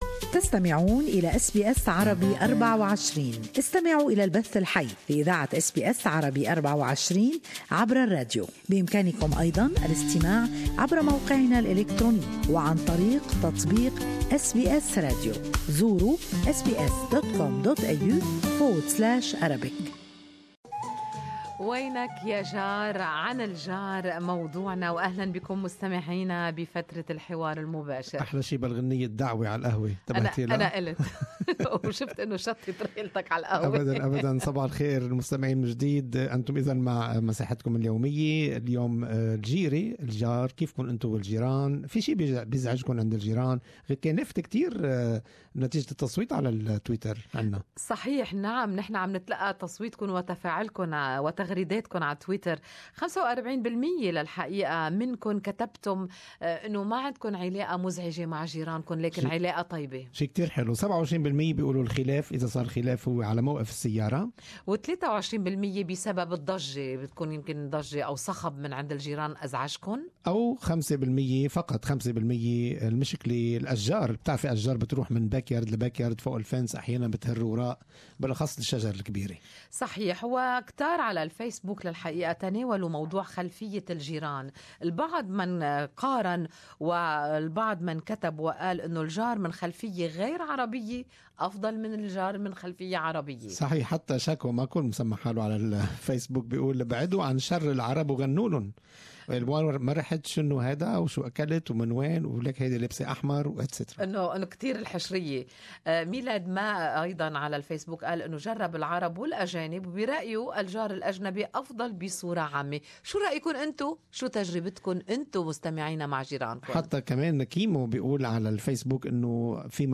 A recently published report shows that biggest reason behind neighbor fights is because of trees! Good Morning Australia listeners share their experiences with us.